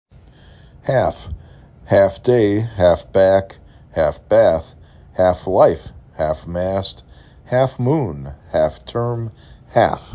4 Letters, 1 Syllable
h a f